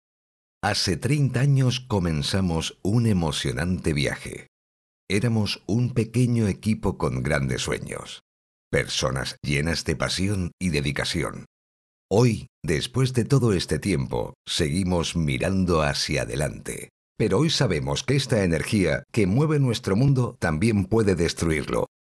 Banco de voces de locutores con acentos regionales de Mallorca, Canarias y de Andalucía
Locutoras de Canarias. Locutores de Canarias. Locutores canarios